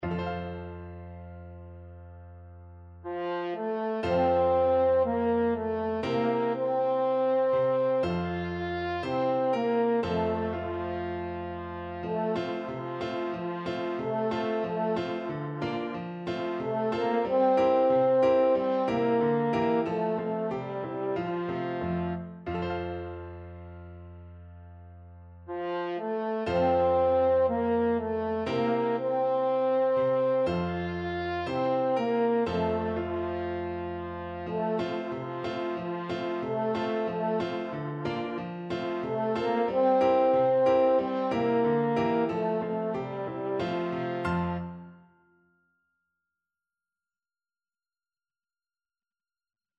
Christmas
2/2 (View more 2/2 Music)
Slow =c.60